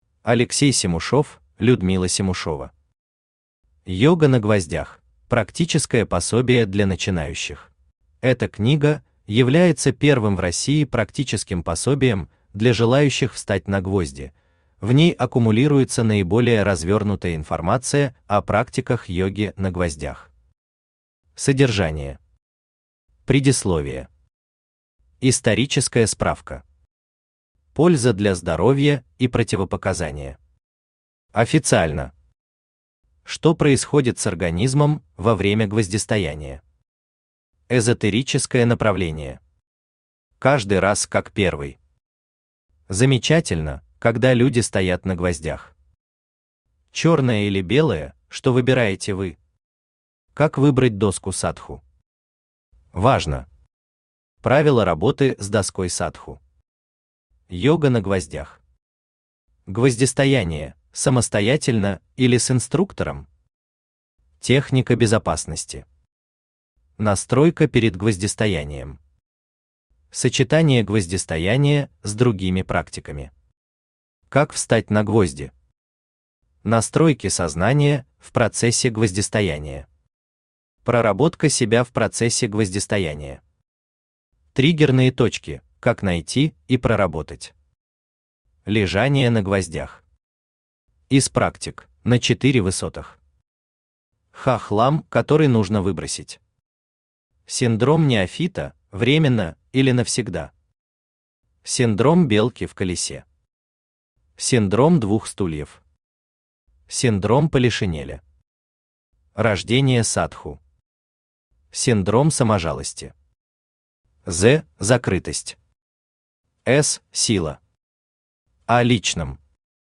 Аудиокнига Йога на гвоздях: практическое пособие для начинающих | Библиотека аудиокниг
Aудиокнига Йога на гвоздях: практическое пособие для начинающих Автор Алексей Семушев Читает аудиокнигу Авточтец ЛитРес.